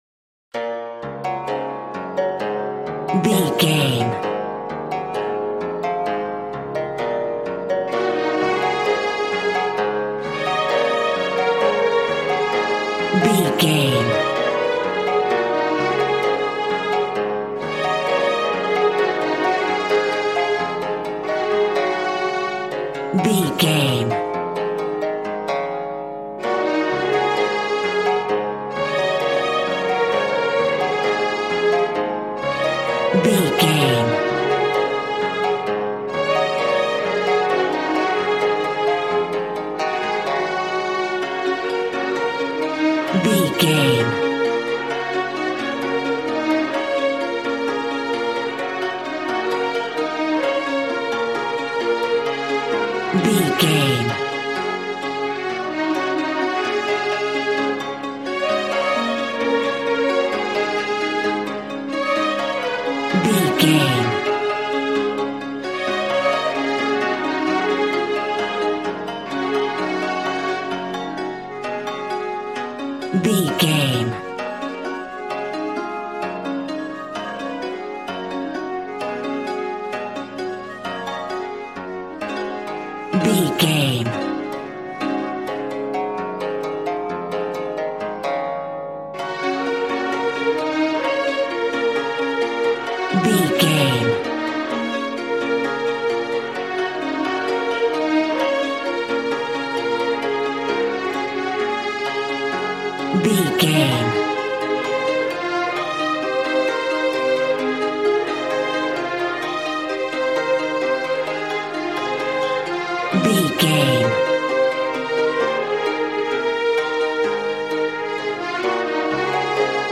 Ionian/Major
D♭
smooth
conga
drums